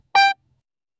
whack